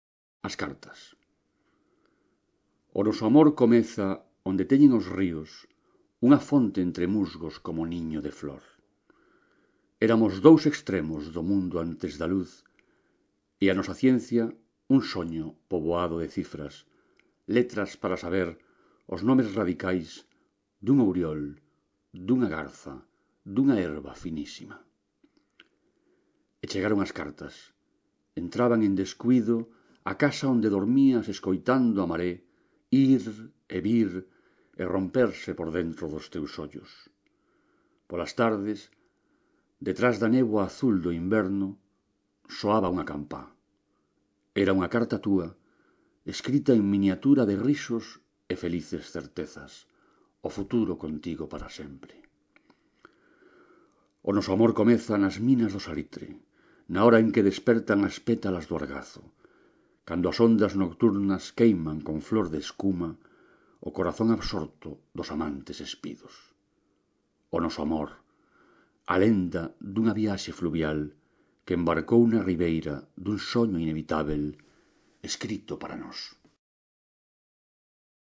Gravaci�n pertencente ao volume Poetas e narradores nas s�as voces , publicado polo Arquivo Sonoro de Galicia (Santiago de Compostela: Consello da Cultura Galega, 2000).